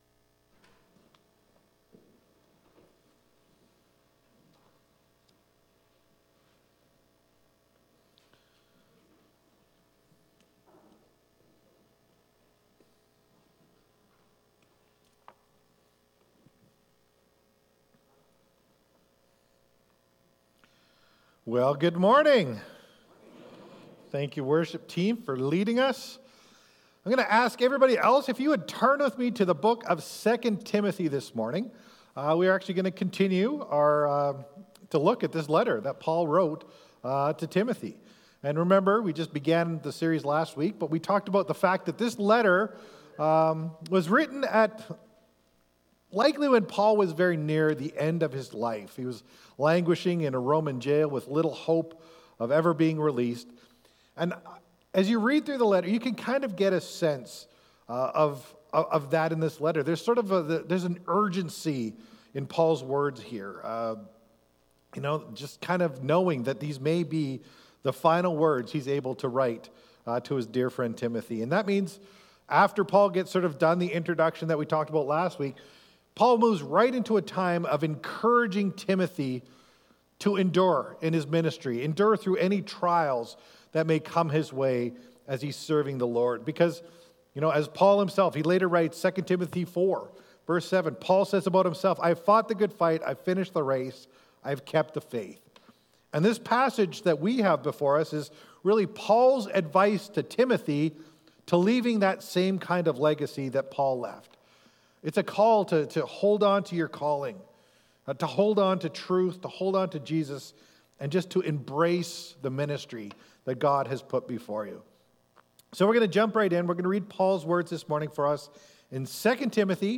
1 Sunday Service 35:01